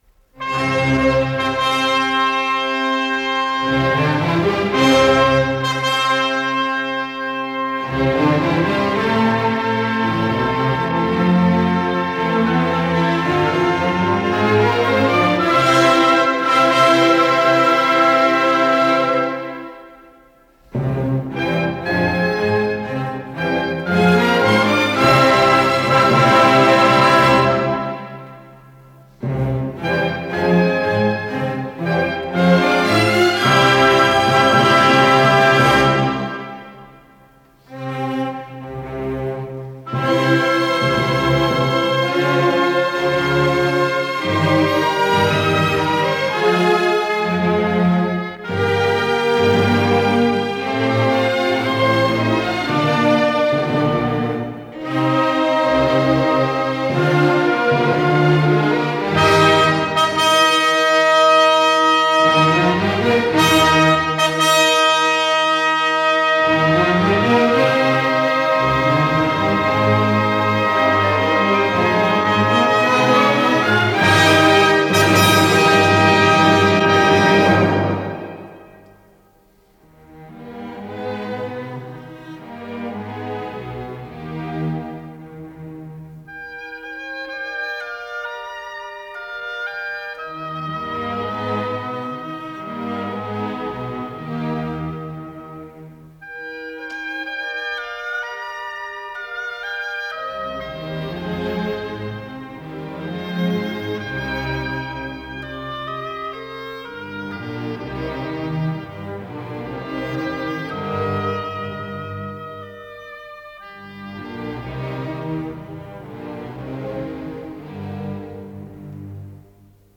Исполнитель: Симфонический оркестр Всесоюзного радио и телевидения
Увертюра на русскую тему для оркестра (Про татарский полон)
До мажор